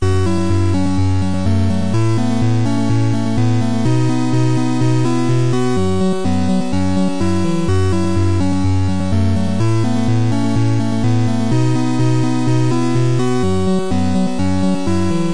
8 Bit Disco Loop
Desgined to be looped.